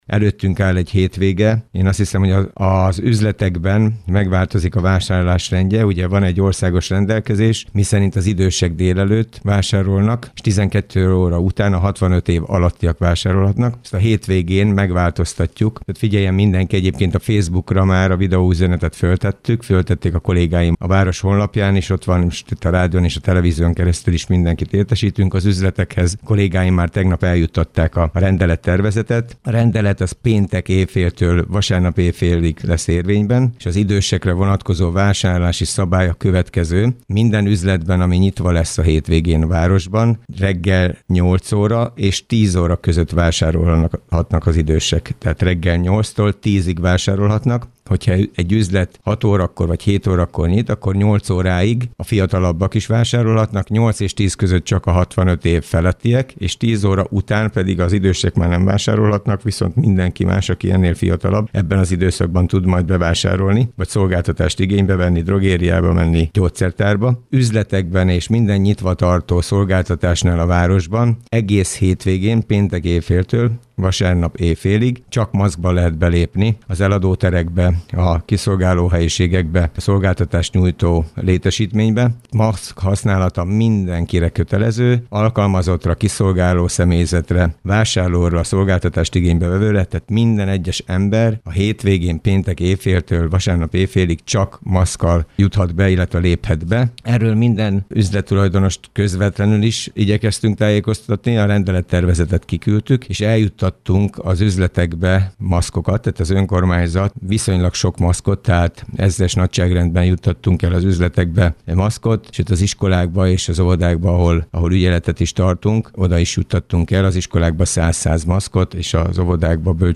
Kőszegi Zoltán polgármestert hallják.